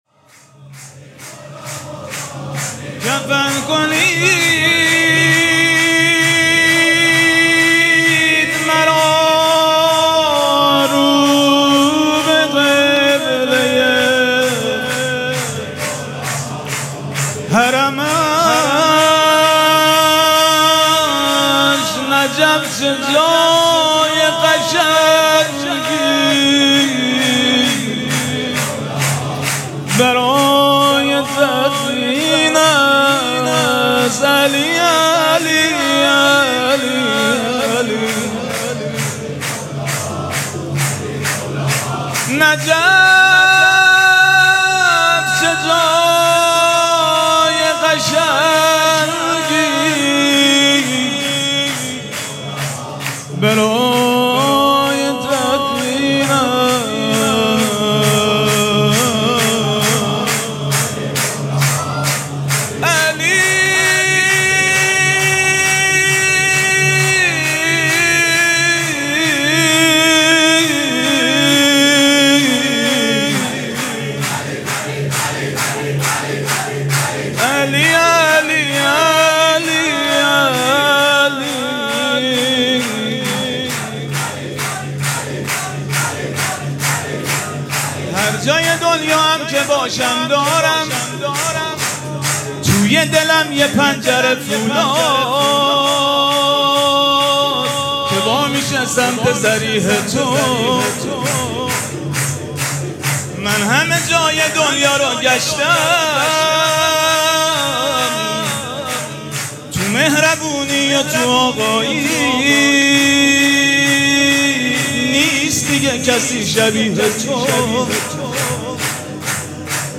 حسینیه ریحانة‌الحسین (سلام‌الله‌علیها)
سرود
ولادت امام رضا علیه السّلام